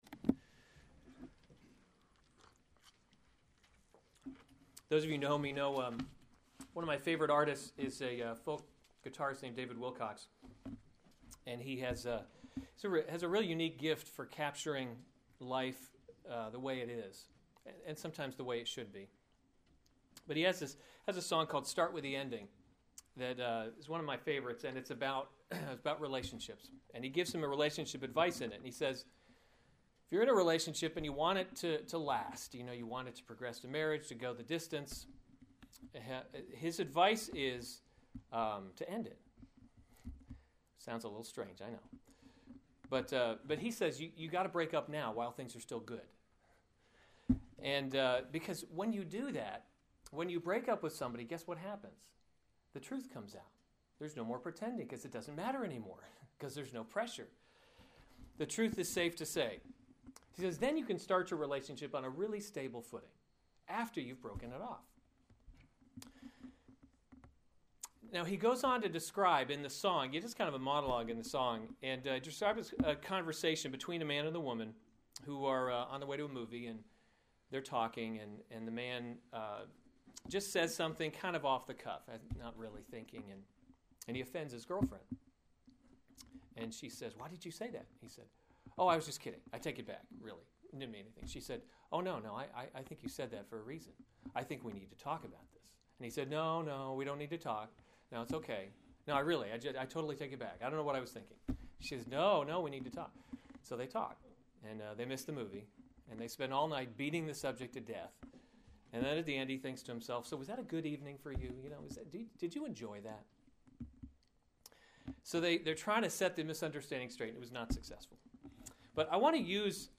April 19, 2014 Special Services series Easter Service Save/Download this sermon Mark 16:1-8 Other sermons from Mark The Resurrection 16:1 When the Sabbath was past, Mary Magdalene and Mary the mother […]